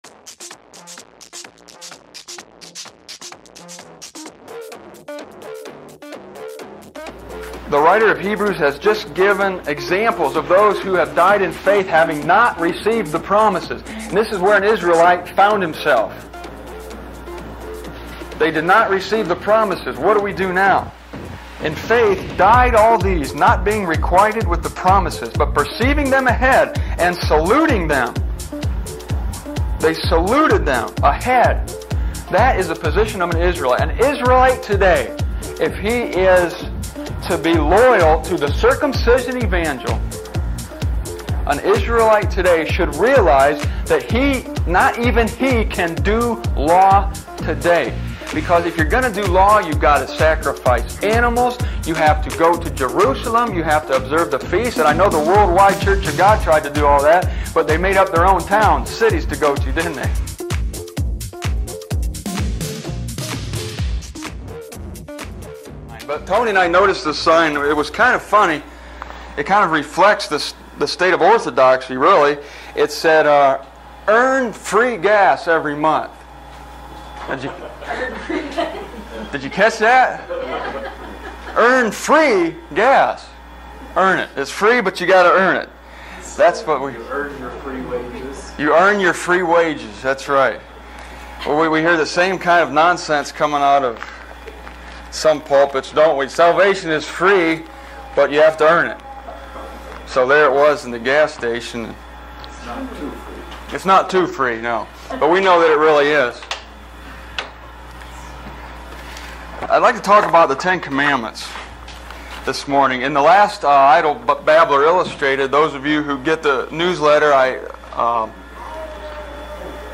I recorded this message in 1995 in Baldwin, MI.